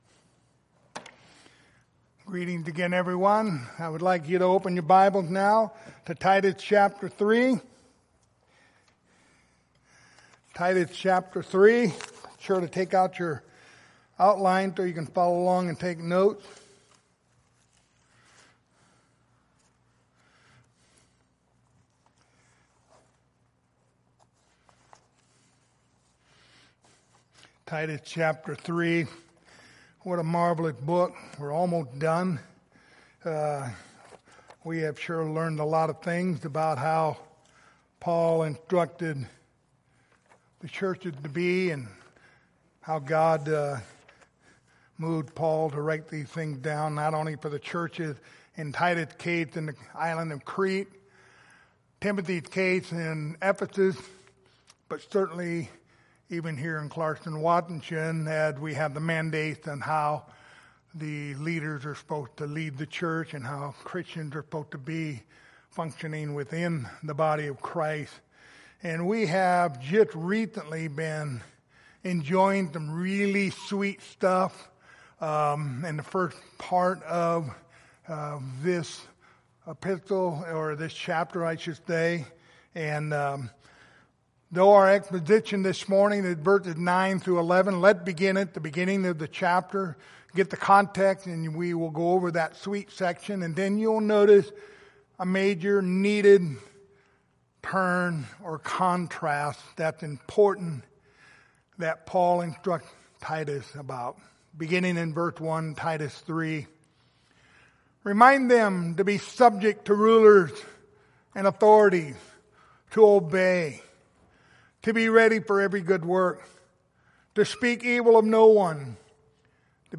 Pastoral Epistles Passage: Titus 3:9-11 Service Type: Sunday Morning Topics